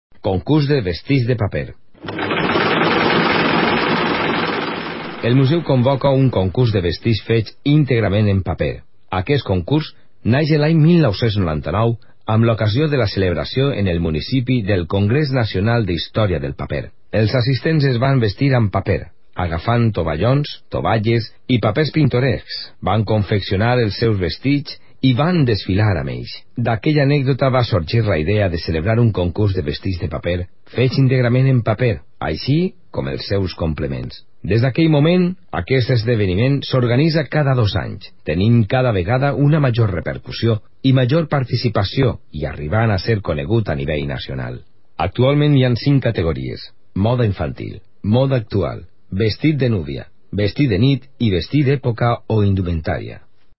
Categoria: Audioguies 1-5